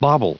Prononciation du mot bauble en anglais (fichier audio)
Prononciation du mot : bauble